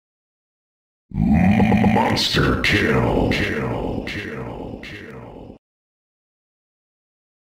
Звук триумфальной победы голоса Monster Kill из Unreal Tournament